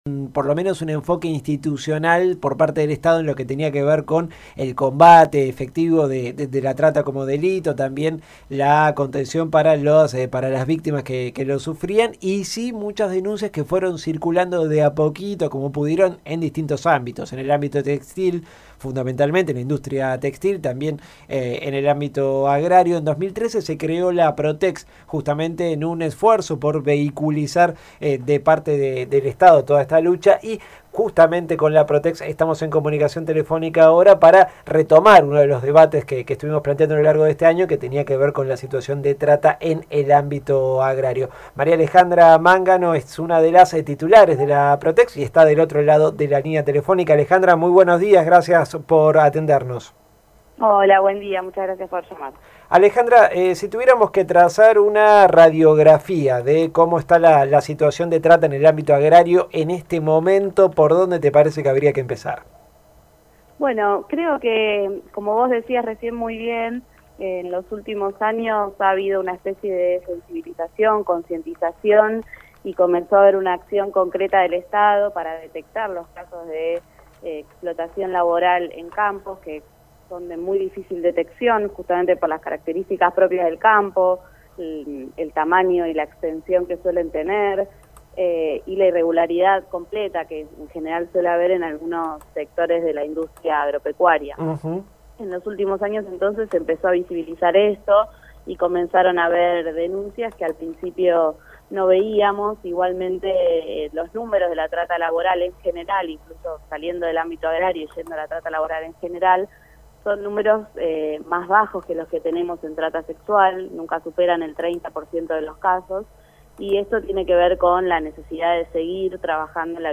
(21/09/16) Caídos del Catre conversó con María Alejandra Mangano, una de las titulares de la Procuraduría de Trata y Explotación de Personas (PROTEX), acerca de los índices sobre explotación de trabajadores en el sector agropecuario.